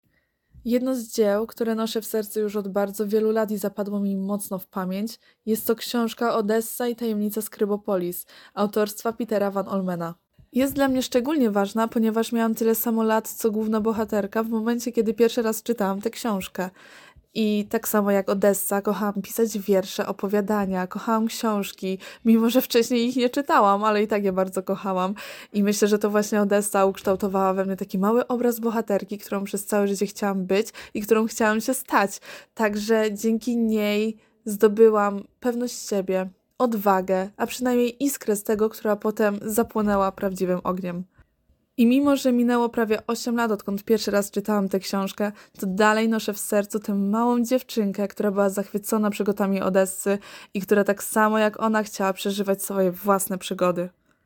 Zapytaliśmy studentów, o wspomnienia dzieł, które towarzyszą im przez całe życie i dlaczego są dla nich tak ważne: